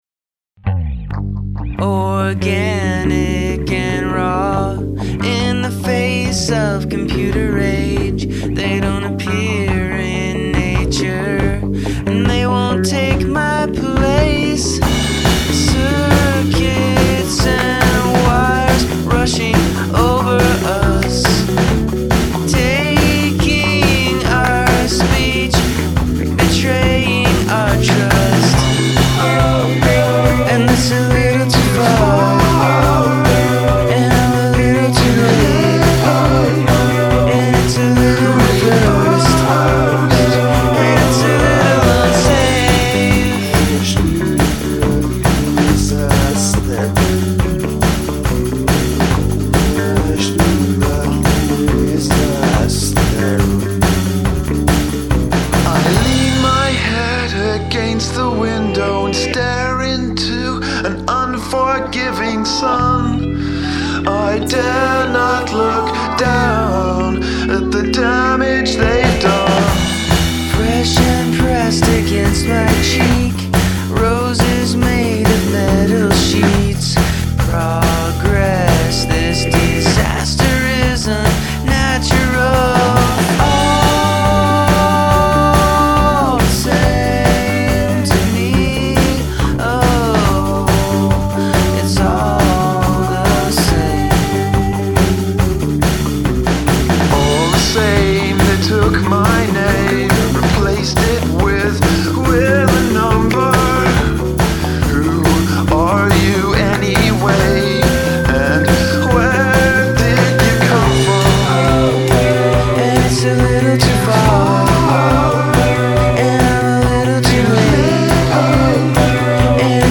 Must include prominent use of backwards recording
The chorus is totally great, with fantastic backing vocals.
Lots of nice change-ups.